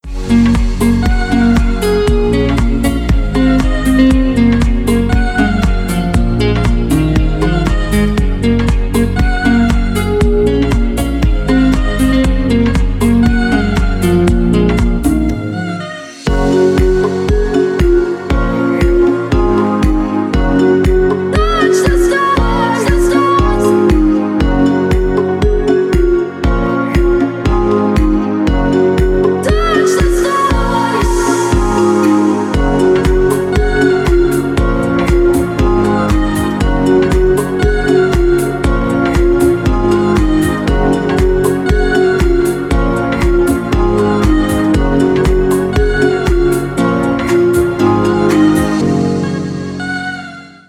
• Качество: 320, Stereo
красивые
deep house
dance